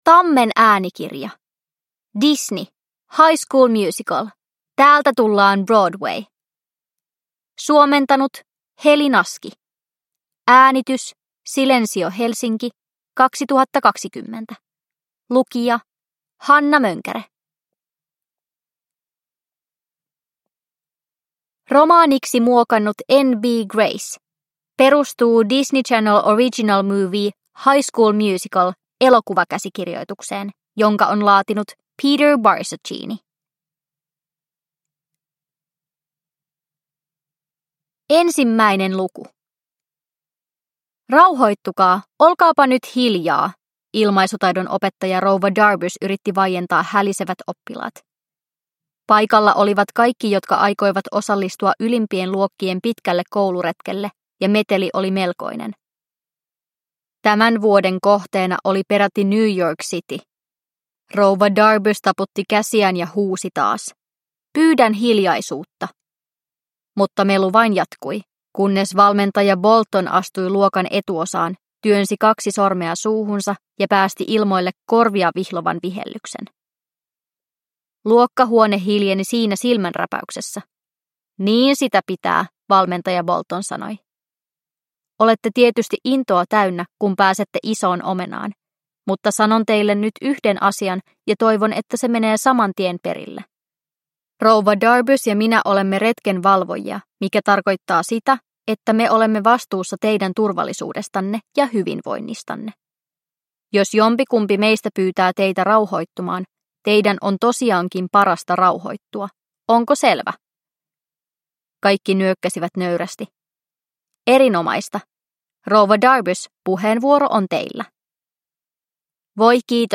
High School Musical. Täältä tullaan, Broadway! – Ljudbok – Laddas ner